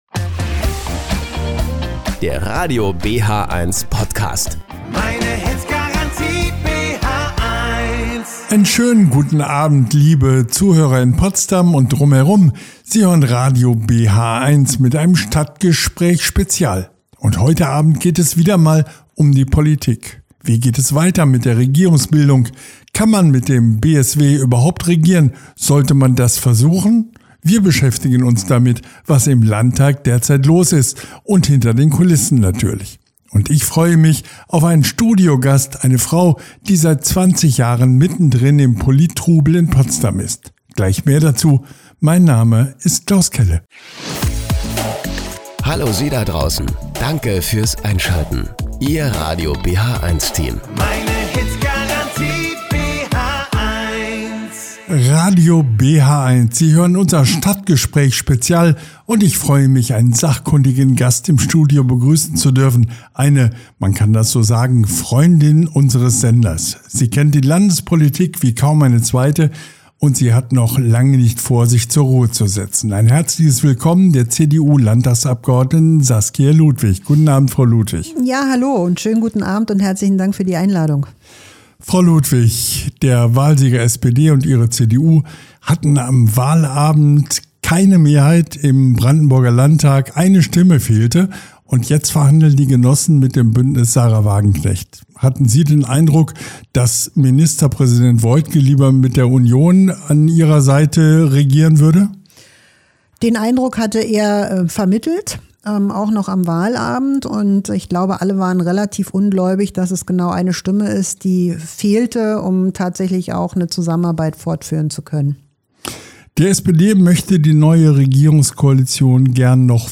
Klaus Kelle im Gespräch mit Saskia Ludwig, Landtagsabgeordnete der CDU in Brandenburg über die Koalitionsverhandlungen und weitere spannende Politik – Themen rund um Potsdam und Brandenburg.